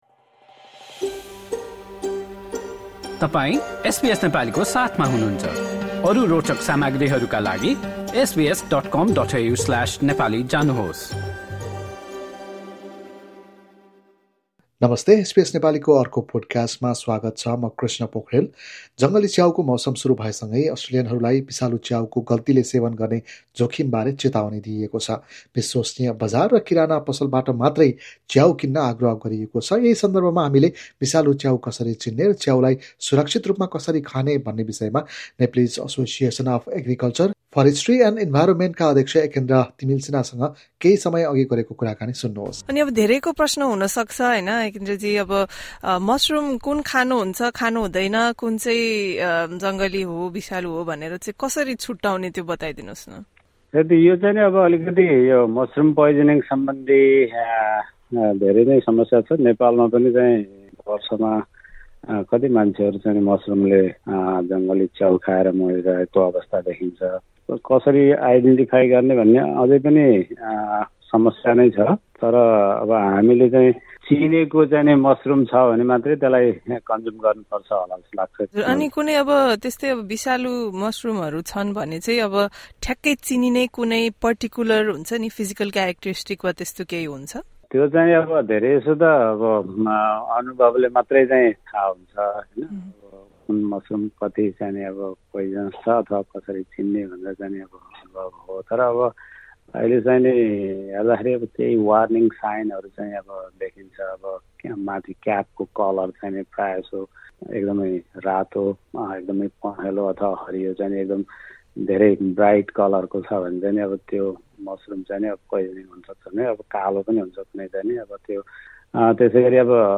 Note: This conversation was first published on 30 May 2025.